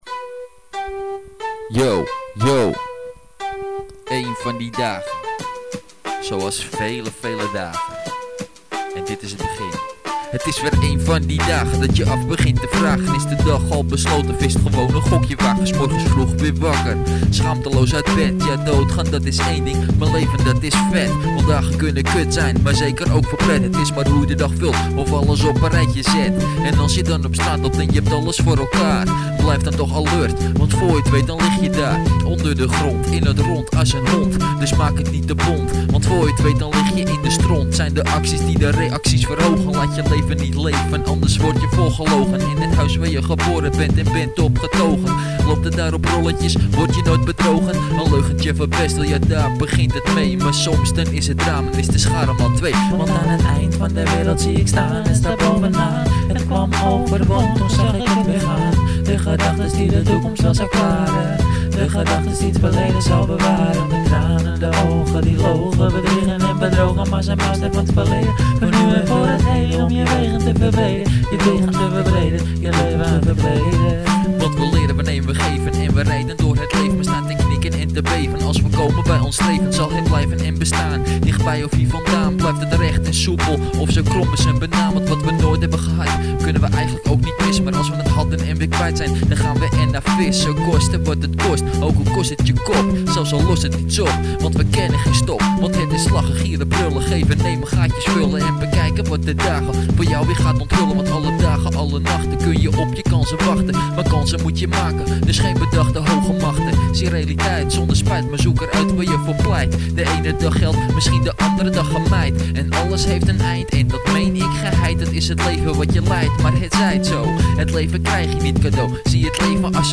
raps!!!!